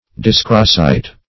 Search Result for " dyscrasite" : The Collaborative International Dictionary of English v.0.48: Dyscrasite \Dys"cra*site\, n. [Gr.
dyscrasite.mp3